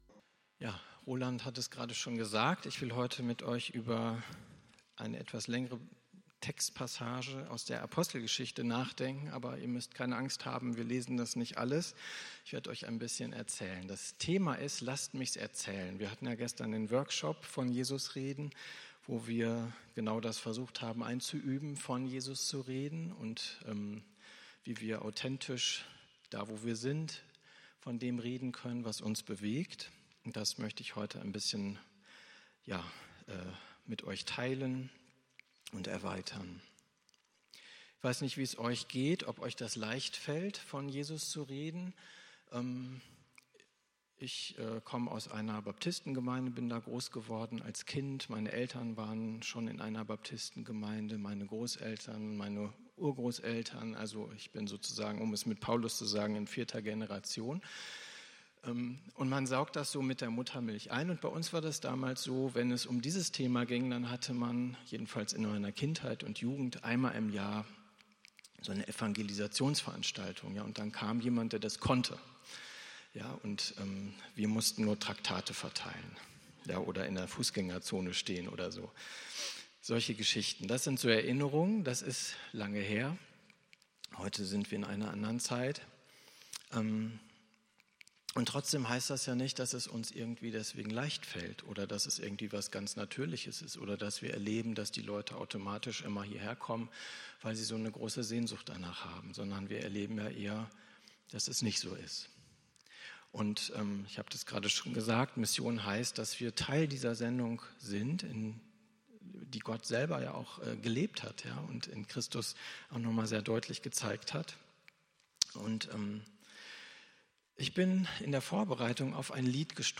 Predigt vom 11.02.2024